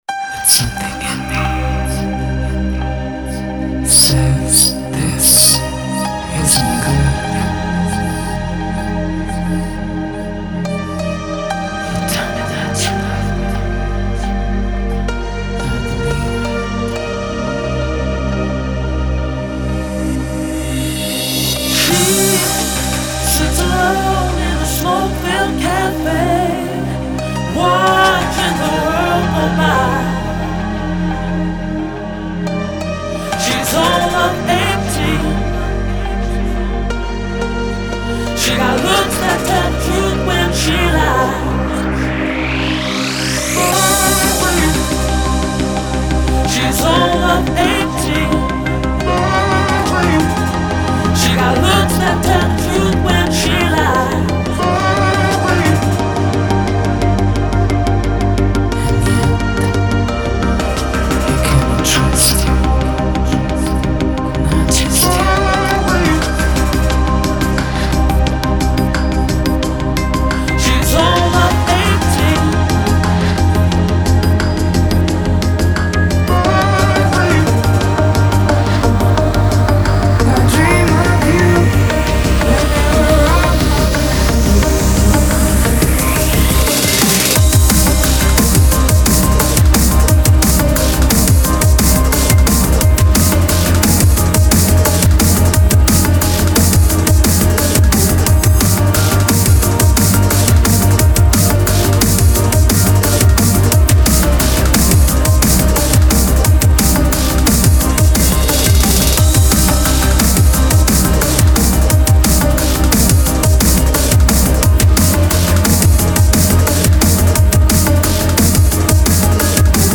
Звучание выделяется яркими синтезаторами и ритмичными битами